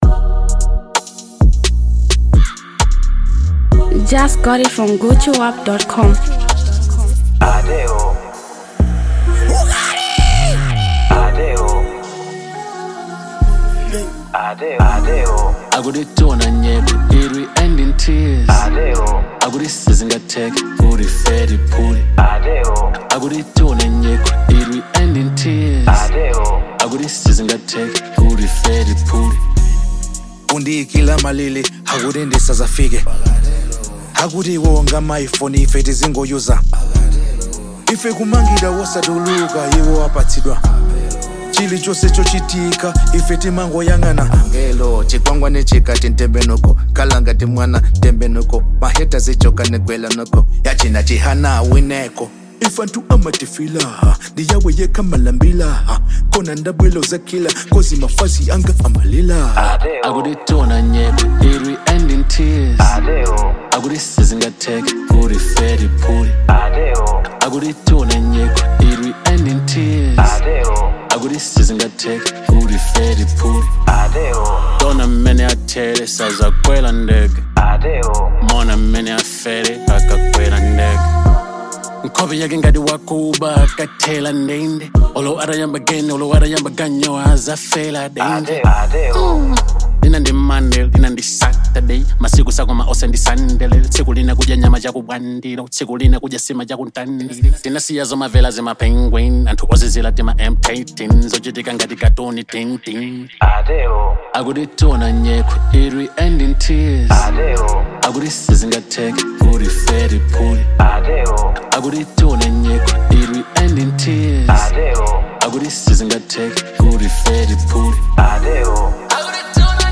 Malawian afro-beat
powerful melodic hit record